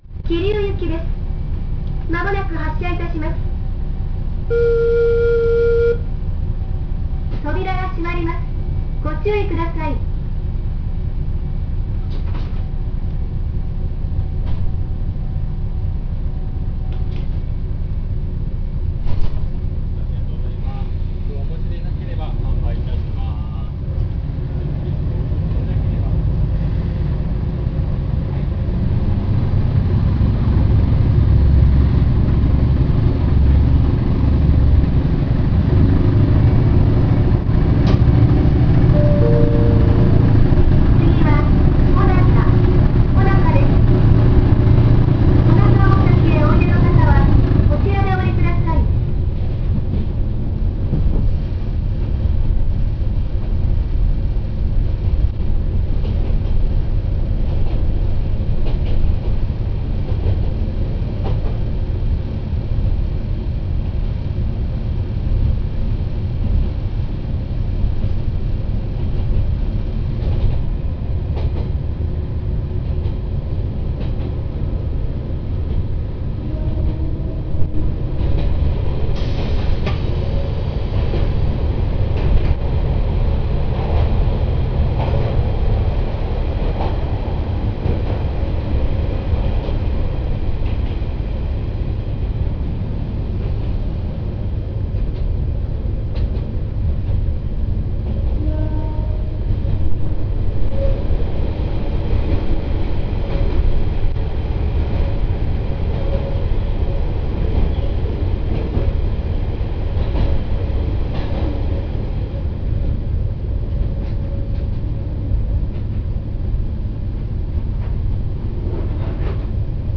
・わ89-310形走行音
【わたらせ渓谷線】神戸〜小中（4分8秒：1.31MB）
走行音自体はごく普通のディーゼルカーの音なのですが、特筆すべきは自動放送による発車案内が入る事。発車ブザーまで設置されている凝りよう。その自動放送の声も、よそでは全く聞いたことが無い声で非常に特徴的。